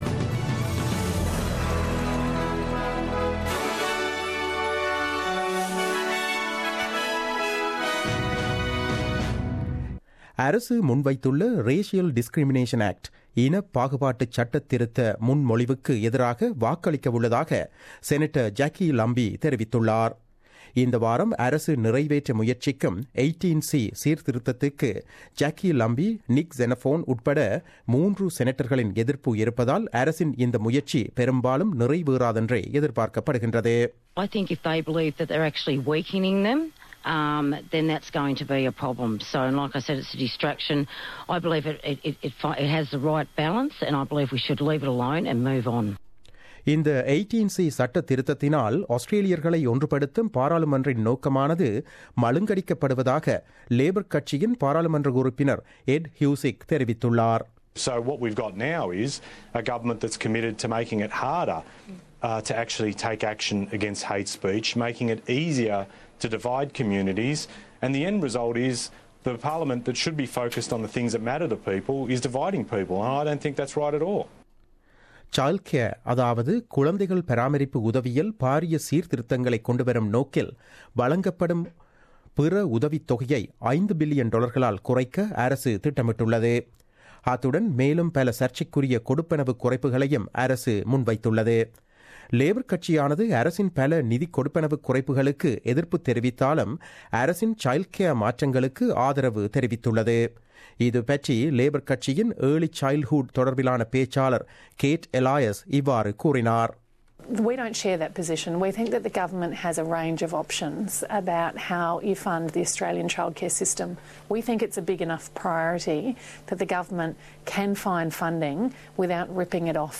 The news bulletin aired on 22 March 2017 at 8pm.